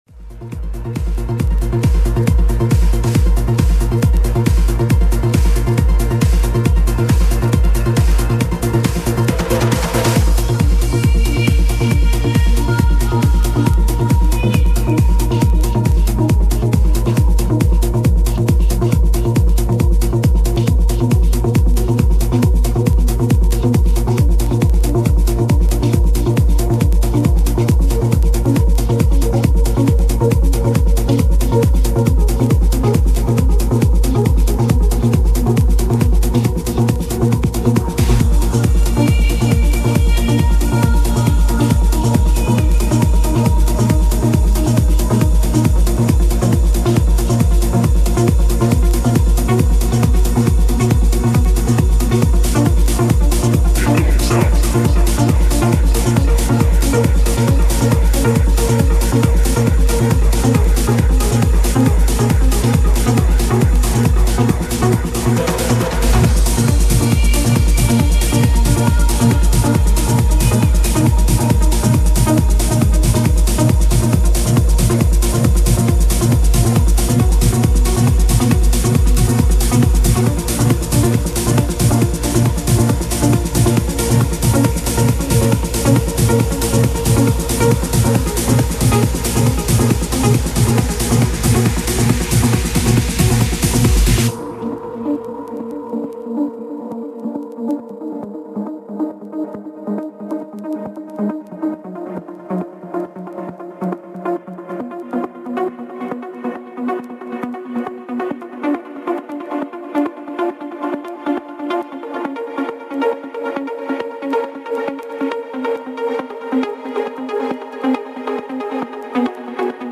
Категория: Club - Mix